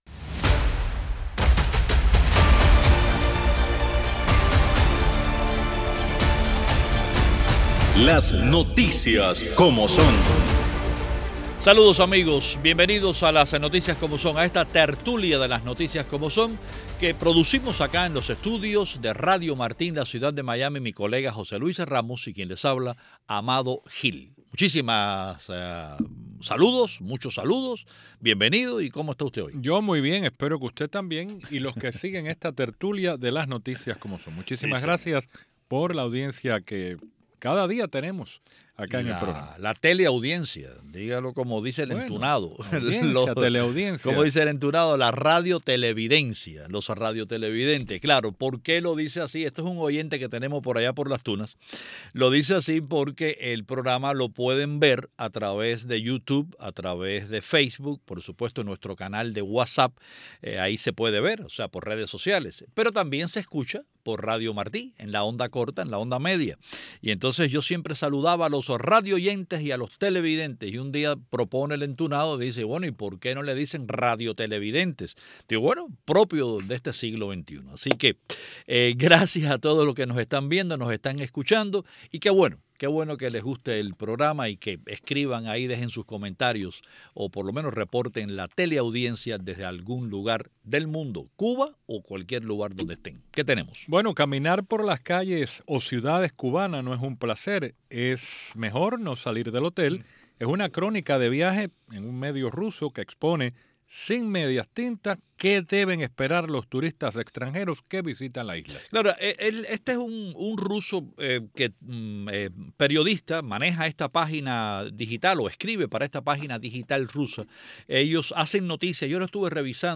Hoy, en la Tertulia de Las Noticias Como Son: Una crónica de viaje en un medio ruso expone sin medias tintas qué deben esperar los turistas extranjeros que visitan la Isla / Cuba sigue registrando datos desastrosos en turismo, con una caída del 23,2% desde enero y mucho más.